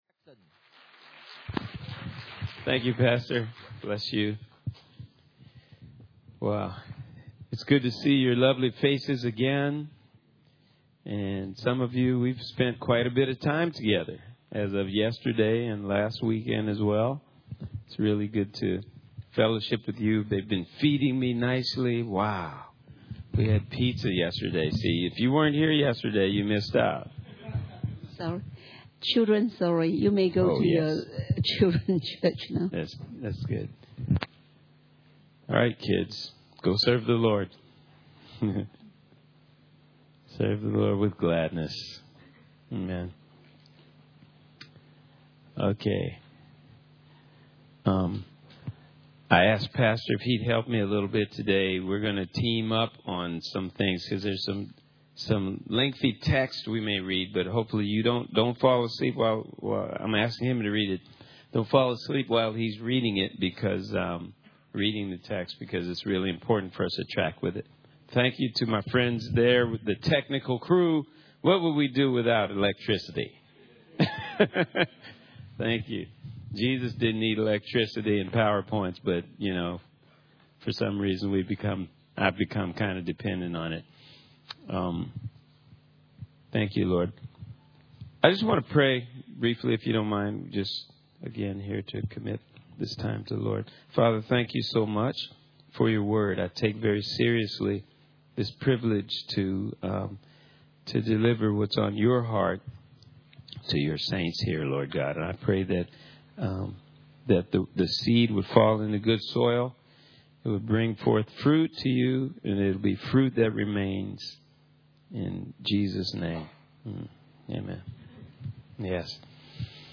Guest Speaker Service Type: Sunday Morning « Sowing